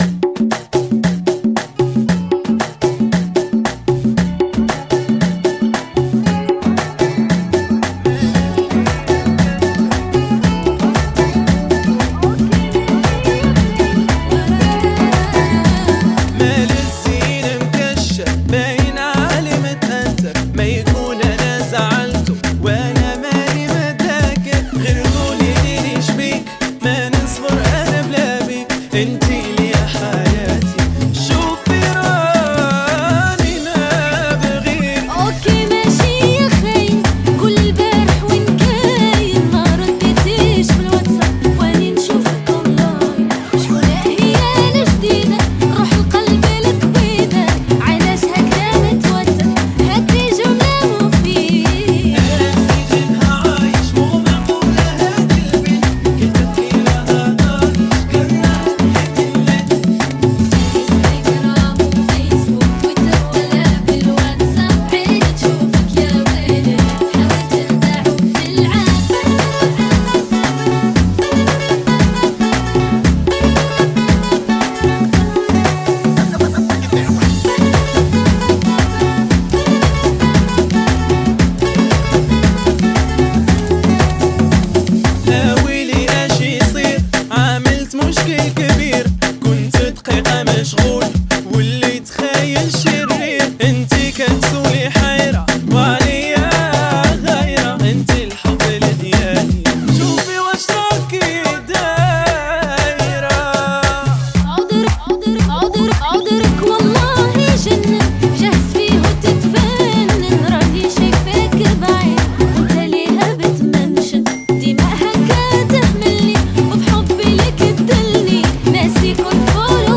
Funky [ 115 Bpm ]